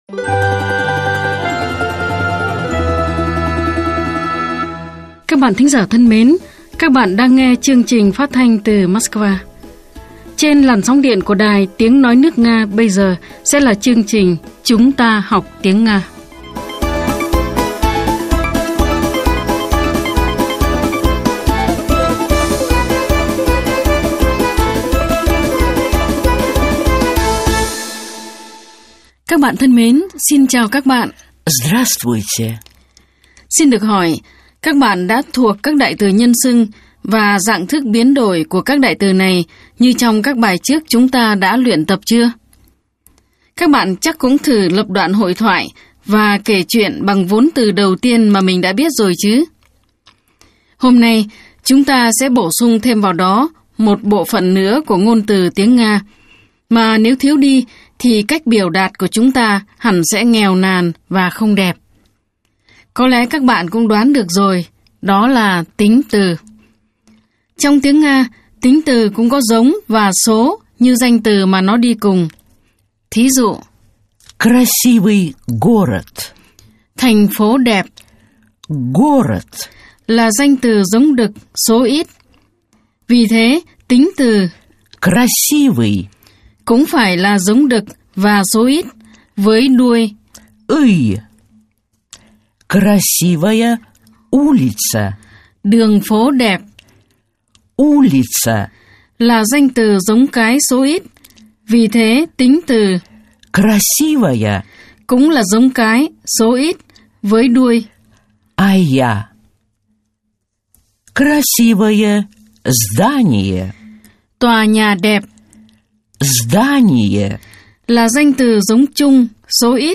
Bài 10 – Bài giảng tiếng Nga
Nguồn: Chuyên mục “Chúng ta học tiếng Nga” đài phát thanh  Sputnik